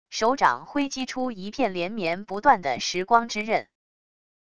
手掌挥击出一片连绵不断的时光之刃wav音频